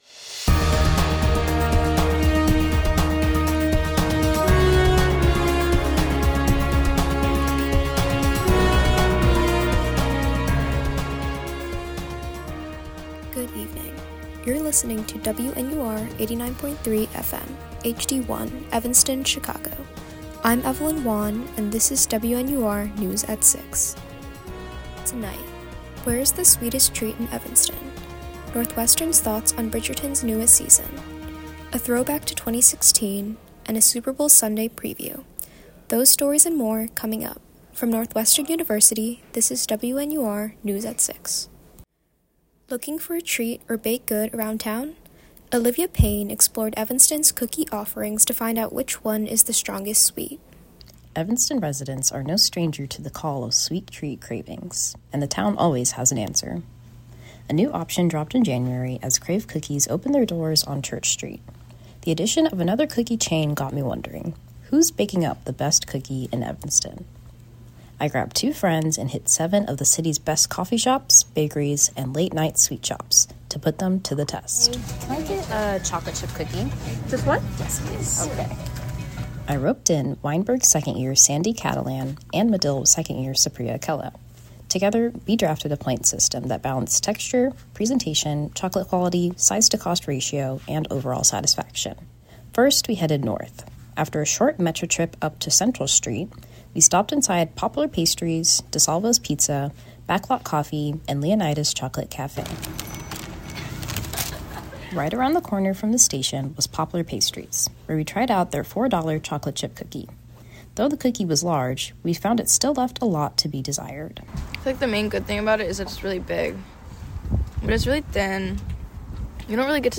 Friday, February 2026: Local sweet treats, Bridgerton, 2016, and a Superbowl Sunday preview. WNUR News broadcasts live at 6 pm CST on Mondays, Wednesdays, and Fridays on WNUR 89.3 FM.